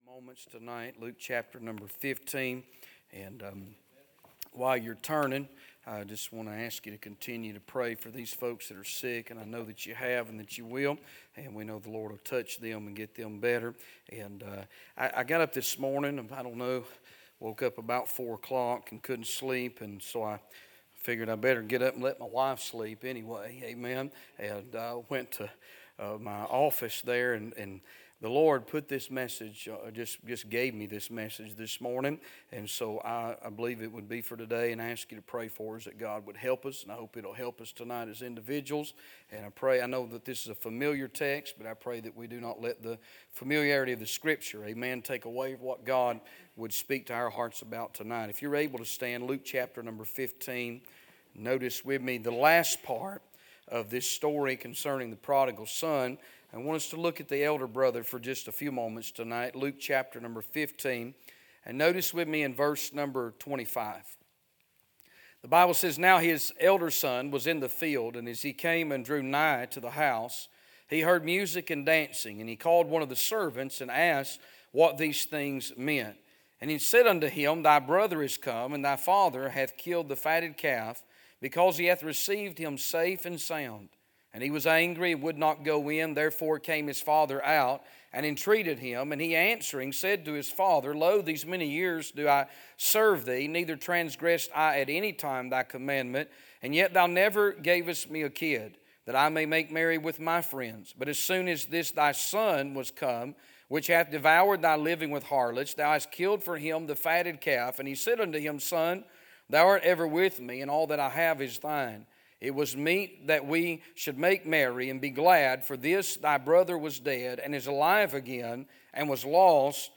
A sermon preached Sunday Evening, on March 30, 2025.